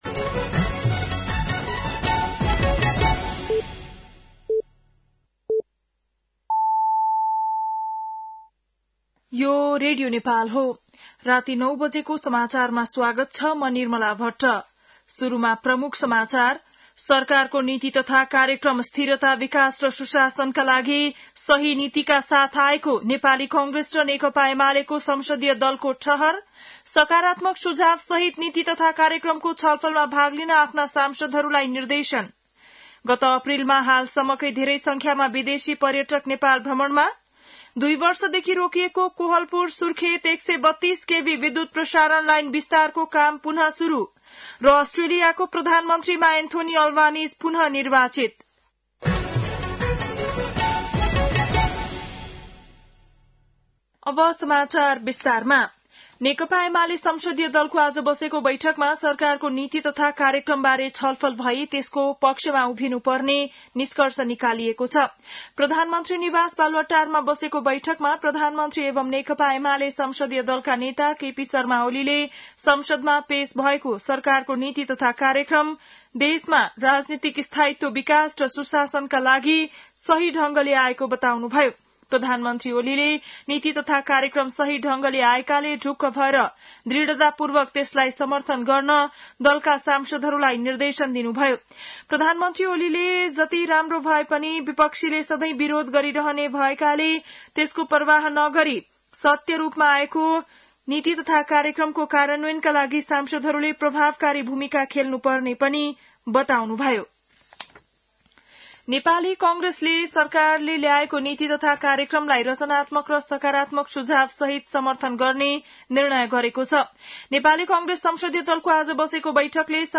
बेलुकी ९ बजेको नेपाली समाचार : २० वैशाख , २०८२